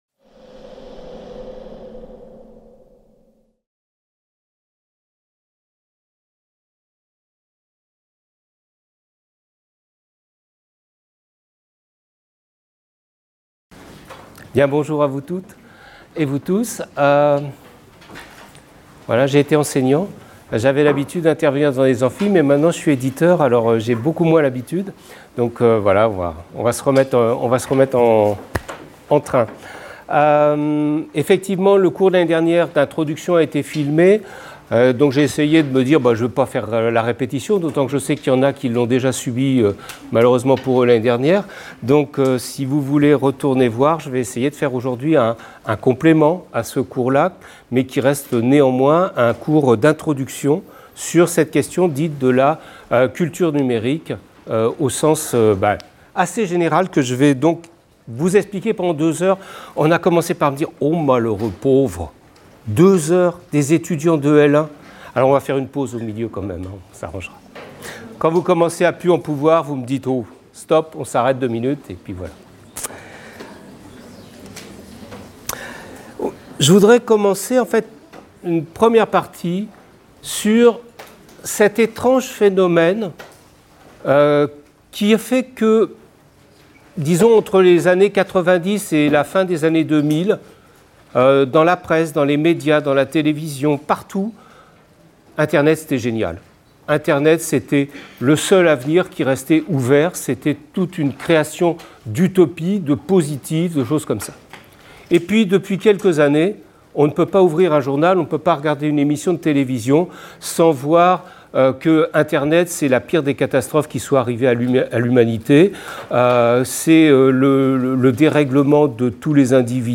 Cours de Culture numérique dans le cadre de la Licence Humanités parcours Humanités numériques.